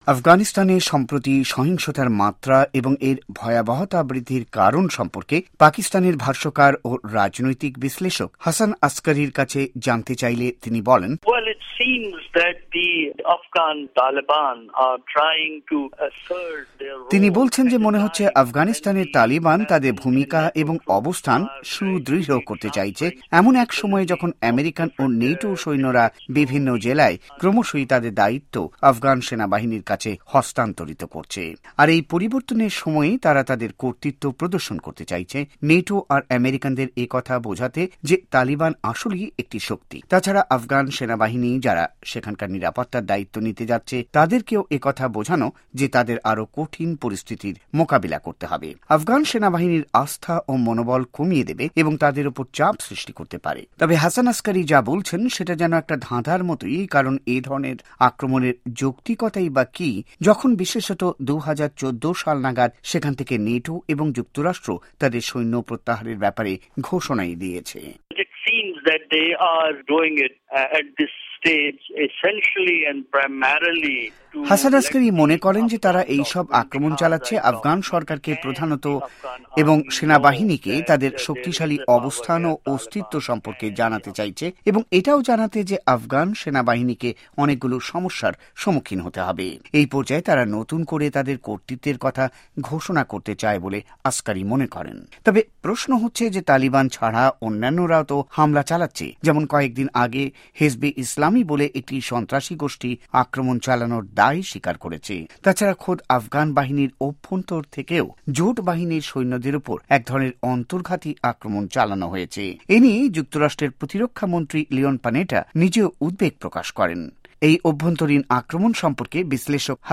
সাক্ষাৎকার ভিত্তিক রিপোর্ট